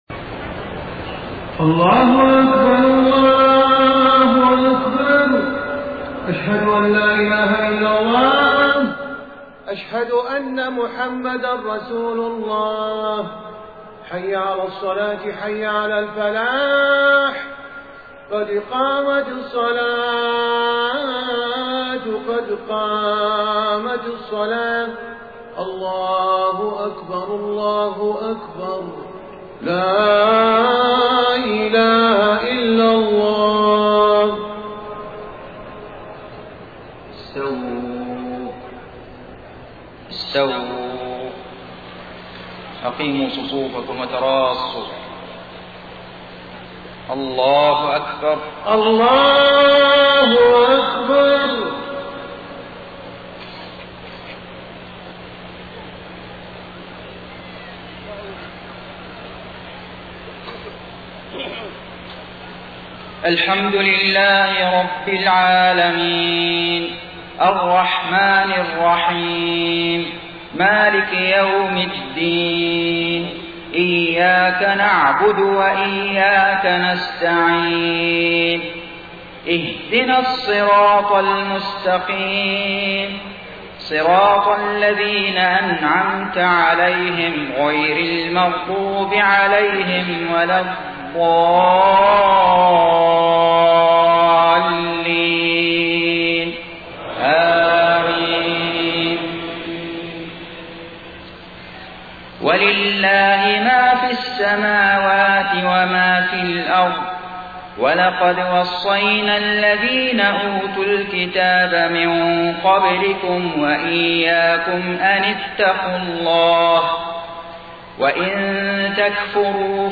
صلاة المغرب 30 صفر 1431هـ من سورة النساء 131-135 > 1431 🕋 > الفروض - تلاوات الحرمين